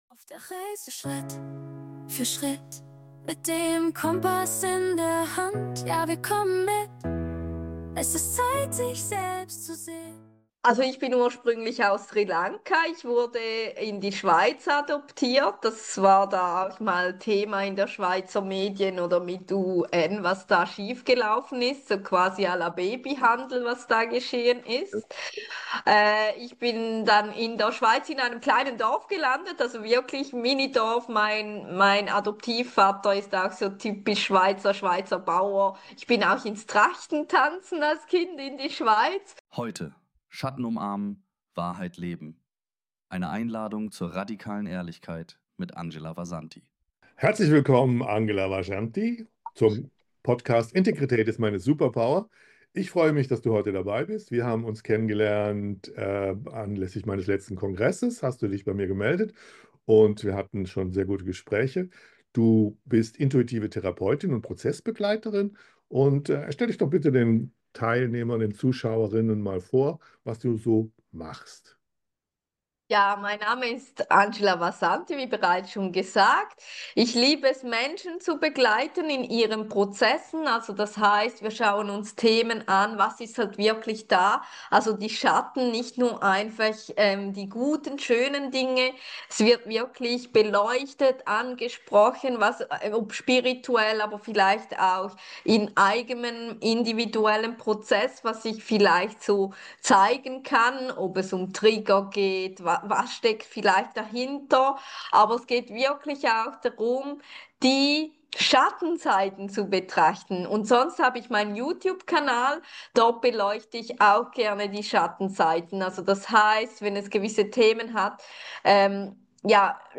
Ein Gespräch über Schatten, Wut und die stille Kraft echter Selbstannahme. Was bedeutet Integrität wirklich?